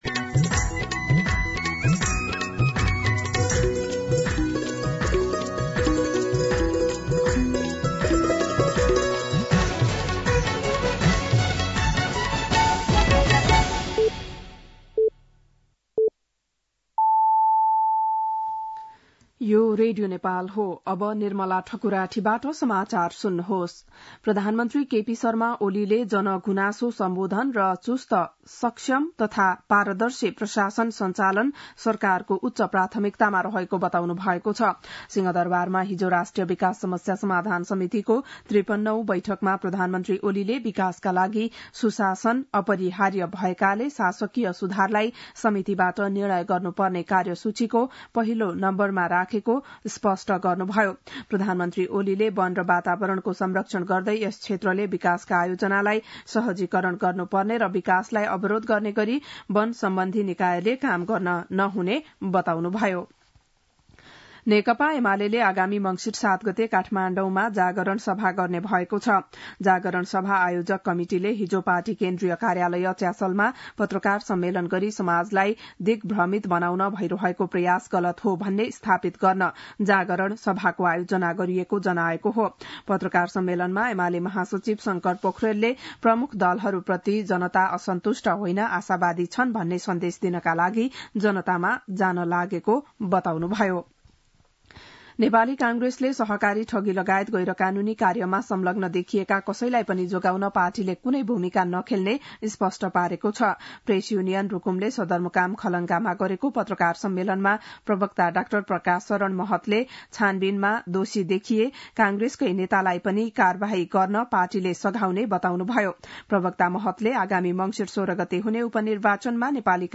बिहान ११ बजेको नेपाली समाचार : २ मंसिर , २०८१
11-am-Nepali-News-4.mp3